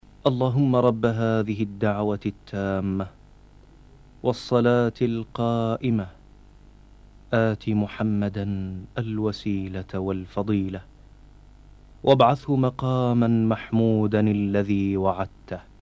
Genre: Adzan.